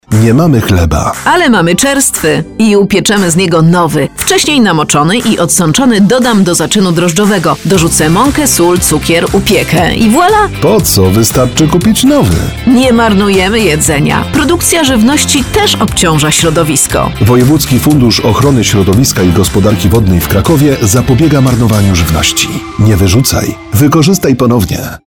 Poniżej znajdziecie Państwo spoty radiowe dotyczące sposobów na wykorzystanie zapasów żywności: